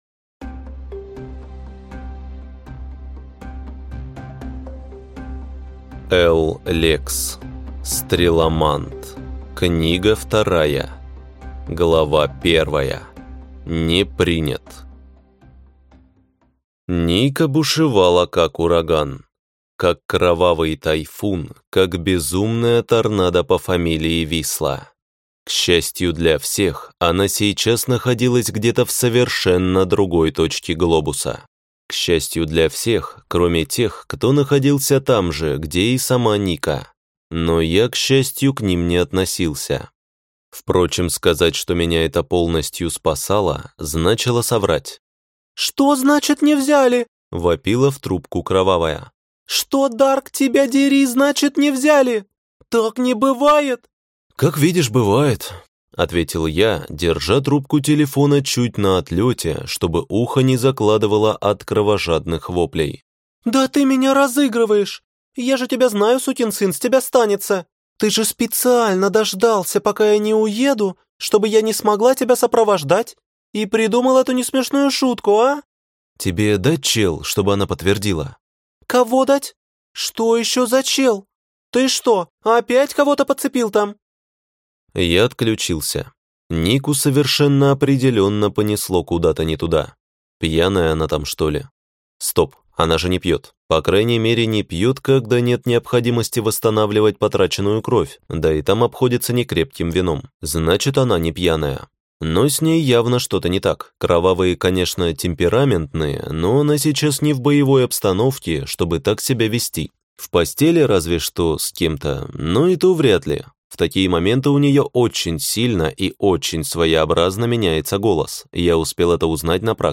Аудиокнига Стреломант. Книга 2 | Библиотека аудиокниг